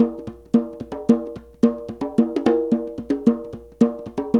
Index of /90_sSampleCDs/Zero G - Ethnic/Partition A/SOLO DJEMBE1
DJEMBE 1  -R.wav